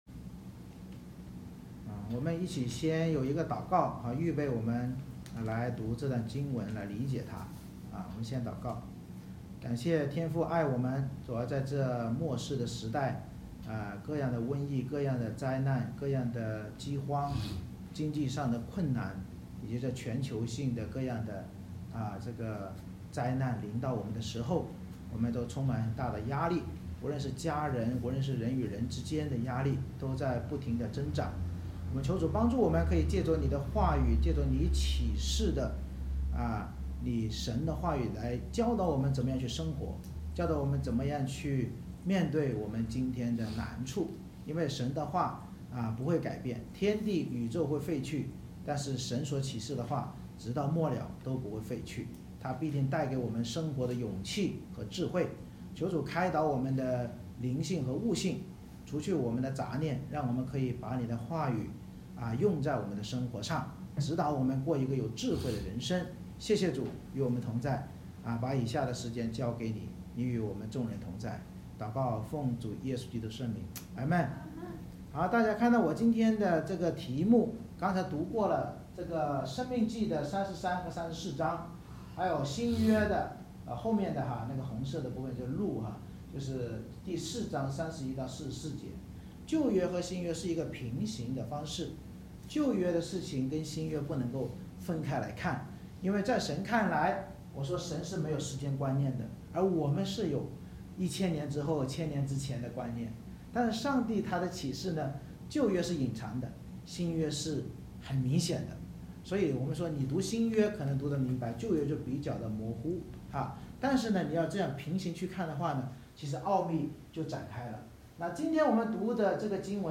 每次崇拜以经文诗歌赞美开始，接着让大人与孩子轮流上台以接力方式读3-4章经文（中/英文）或角色扮演，并简单分享，然后由牧师按新书《圣经导读新唱365》内容进行归纳解释与应用，最后再唱经文诗歌来回应。
Service Type: 主日崇拜